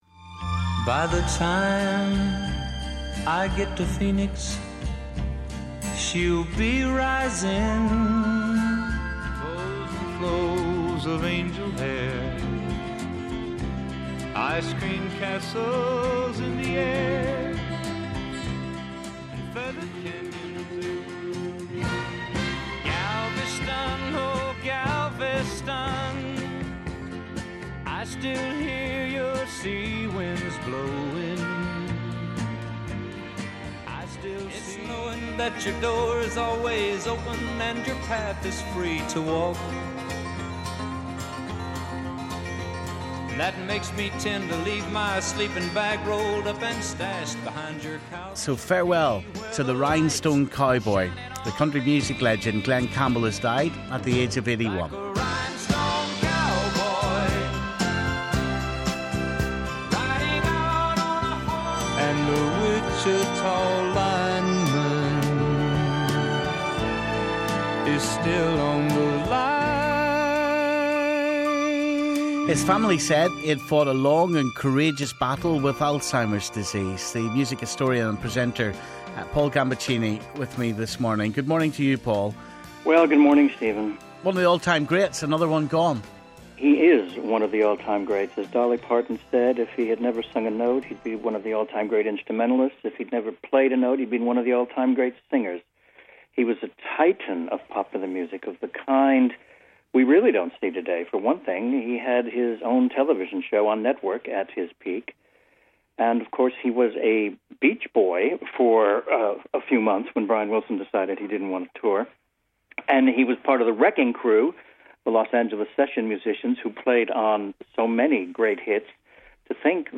'One of the all-time greats' - broadcaster Paul Gambaccini on death of Glen Campbell
Stephen got reaction from the music historian and presenter Paul Gambaccini.